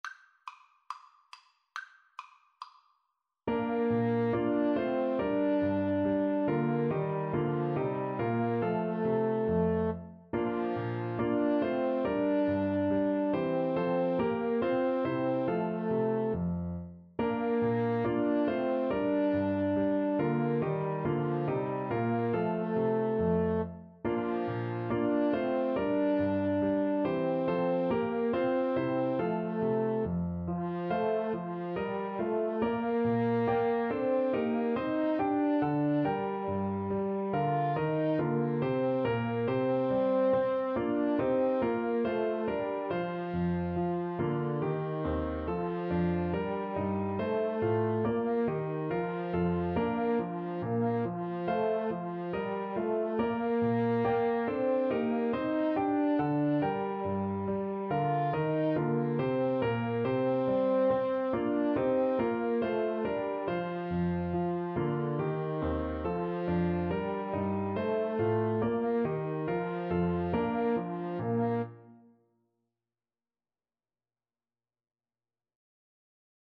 Play (or use space bar on your keyboard) Pause Music Playalong - Player 1 Accompaniment reset tempo print settings full screen
Bb major (Sounding Pitch) (View more Bb major Music for Trumpet-French Horn Duet )